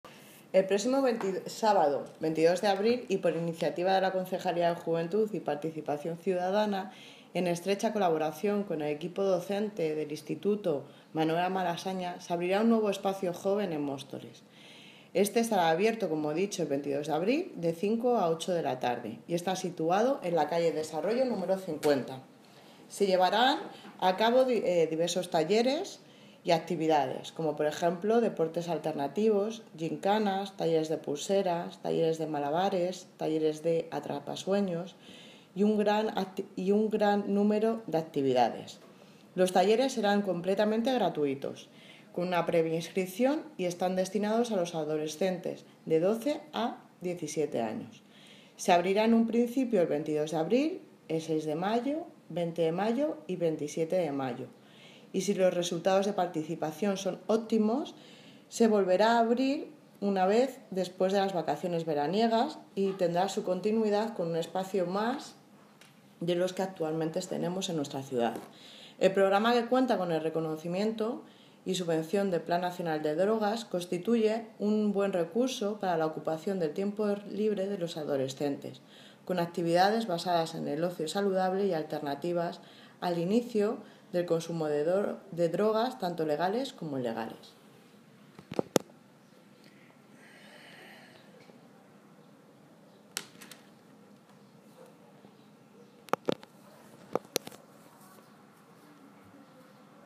Audio - Aránzazu Fernandez (Concejala de Juventud y Participación Ciudadana) Sobre Espacio Joven Malasaña